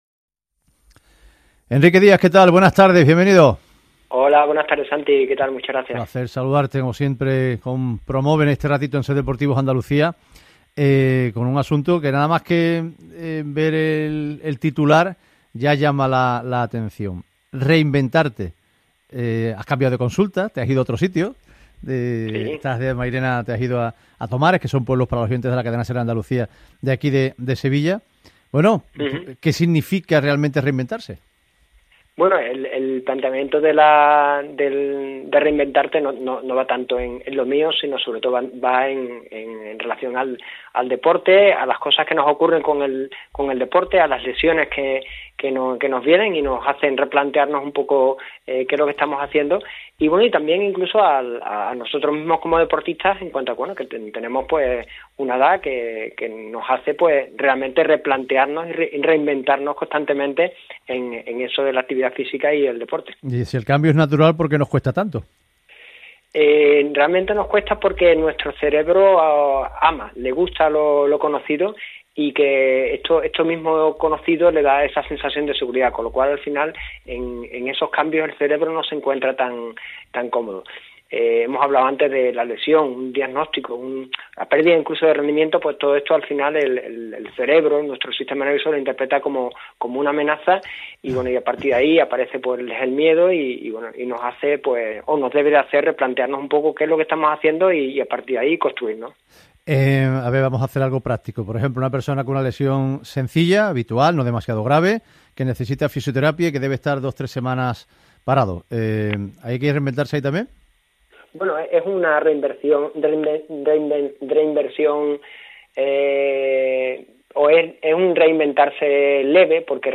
Programa emitido el 22 de febrero de 2026